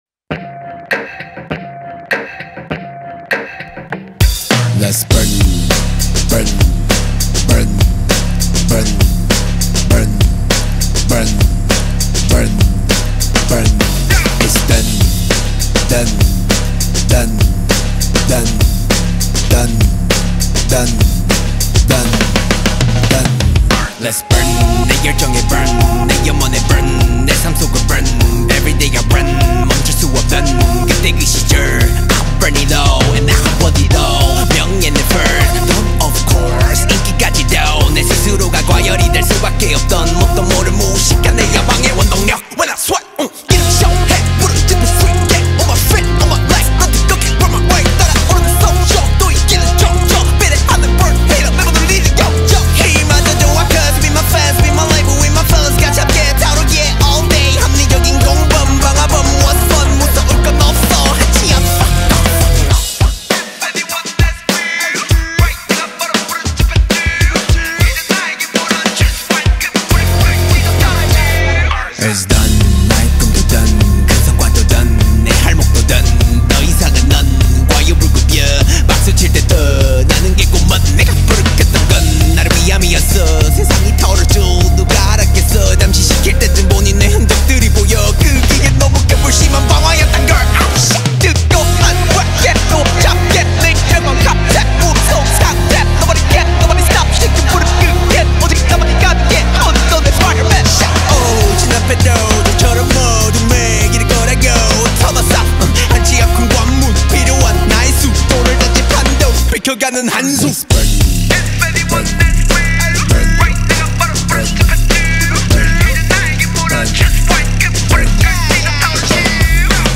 • Качество: 320 kbps, Stereo
рок версия